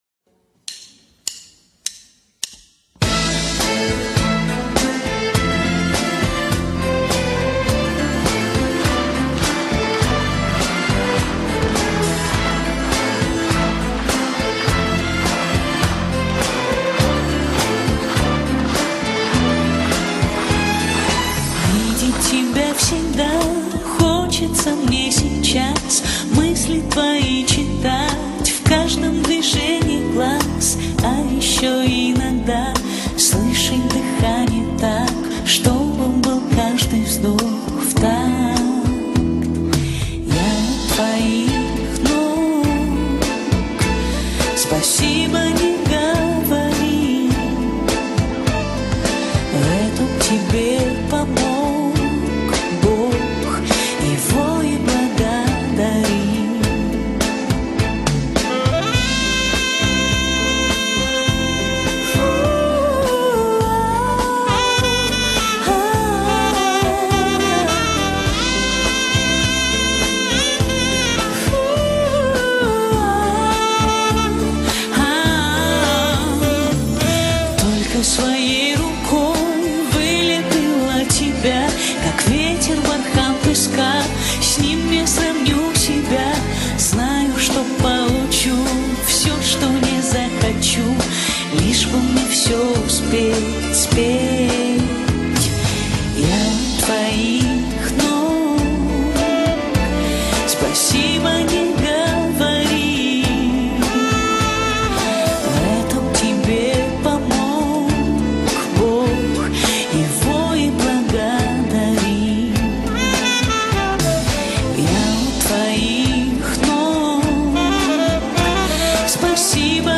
рип с концерта